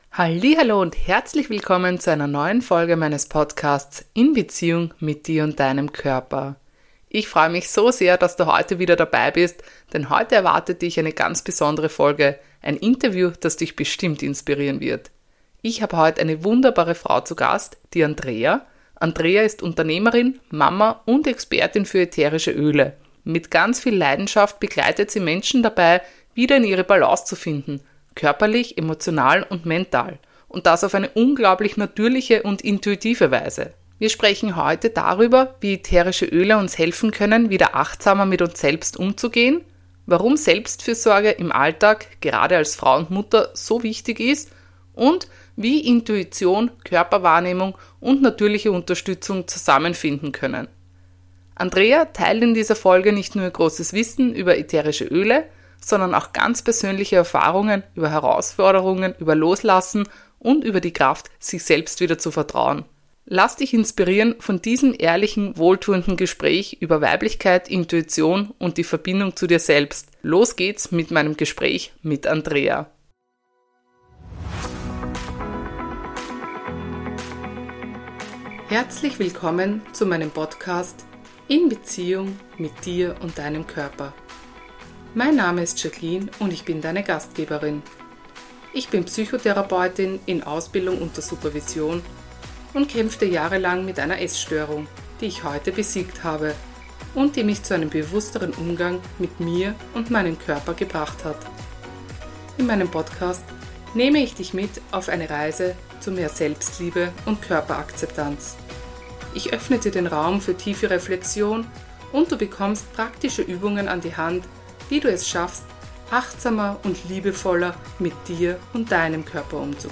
Wir reden über die Kraft der Achtsamkeit, über Intuition und darüber, wie uns natürliche Essenzen im hektischen Alltag helfen können, wieder mehr bei uns selbst anzukommen. Ein ehrliches, warmes Gespräch über Selbstfürsorge, Weiblichkeit und den Mut, auf den eigenen Körper zu hören.